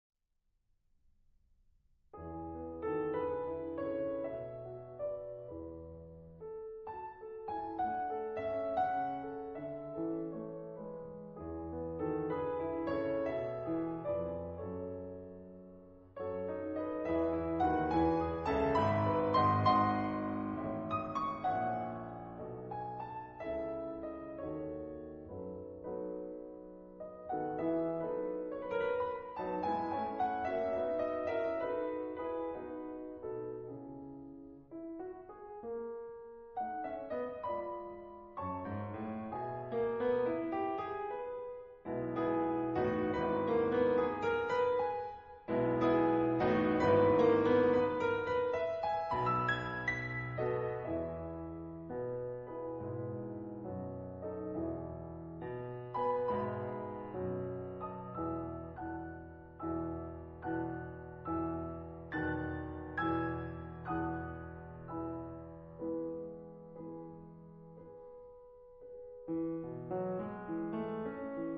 古典音樂